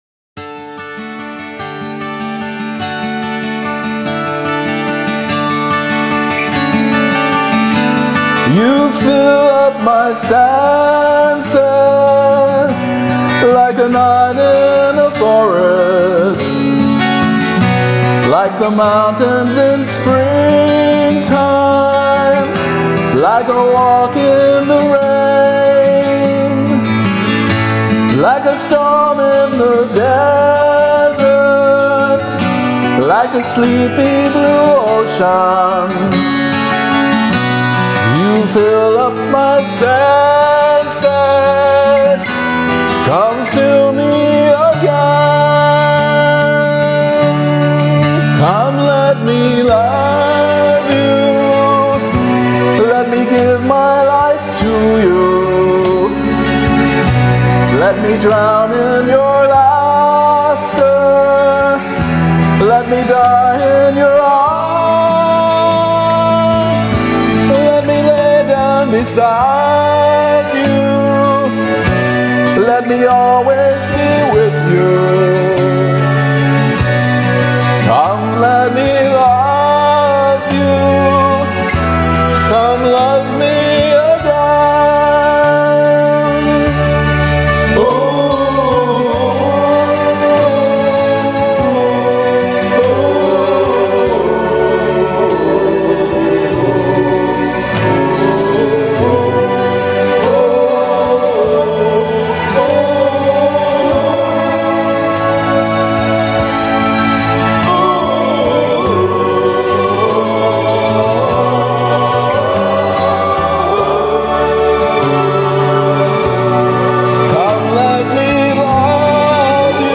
It's a waltz!
Singing style.